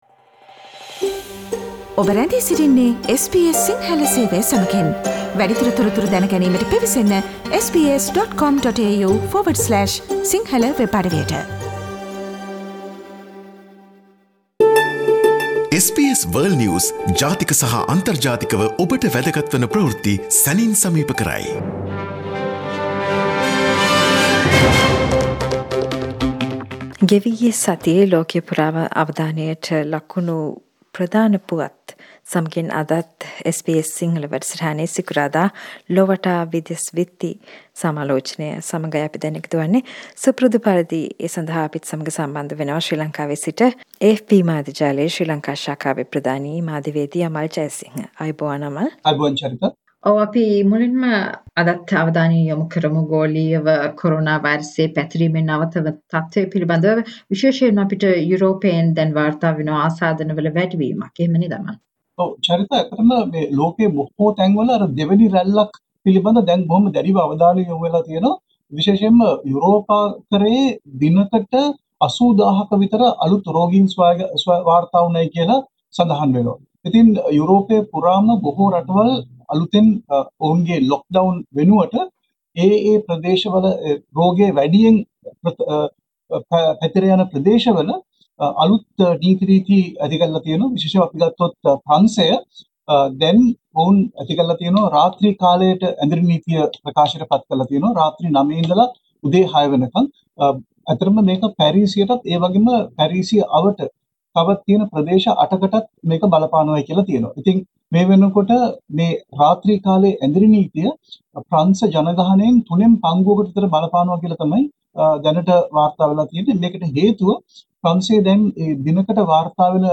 Europe's increasing covid cases, American secretary of State visits India & Sri Lanka, on SBS Sinhala World News wrap